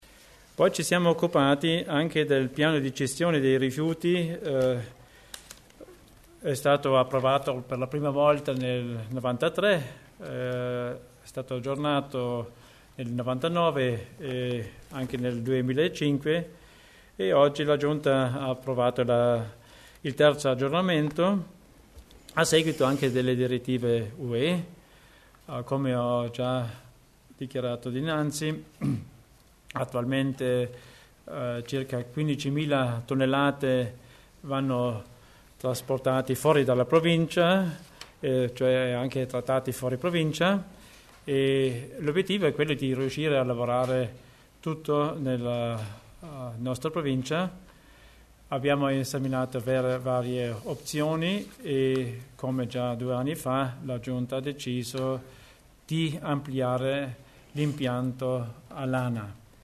L¿Assessore Theiner spiega il nuovo piano provinciale gestione rifiuti